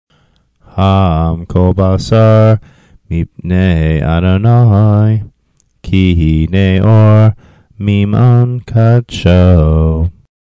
Haftarah Trope Class audio files